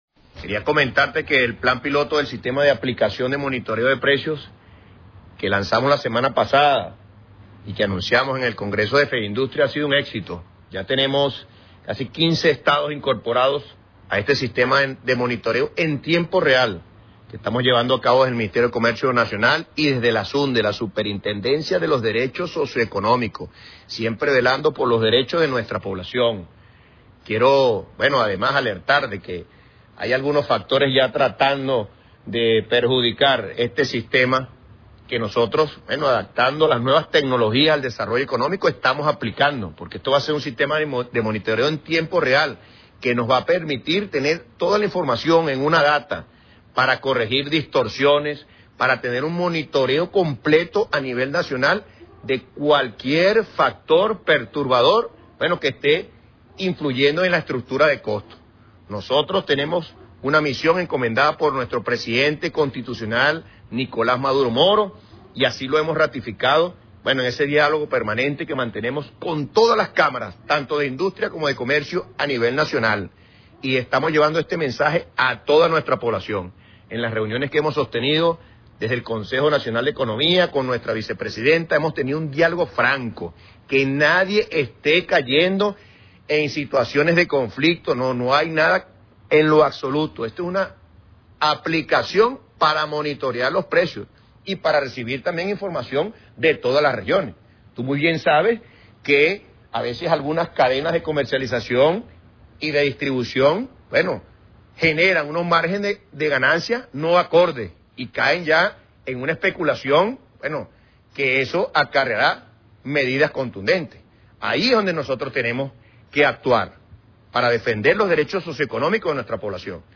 Cnel. Antonio Morales, Ministro de Comercio Nacional
El Ministro de Comercio Nacional, Coronel Antonio Morales, explicó en el programa radial 2+2